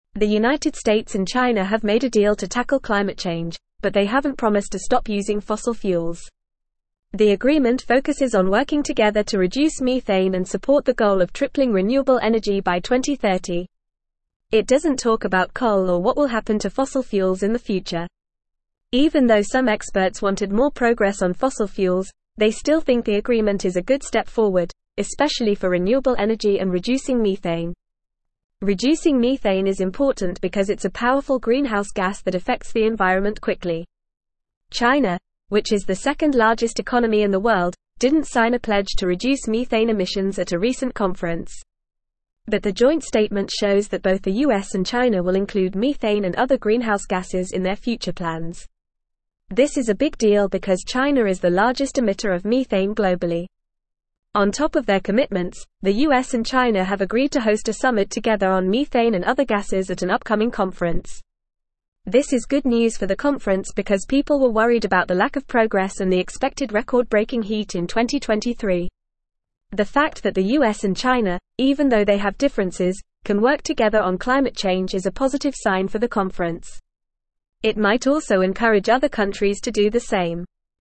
Fast
English-Newsroom-Upper-Intermediate-FAST-Reading-US-and-China-Reach-Climate-Agreement-Address-Methane.mp3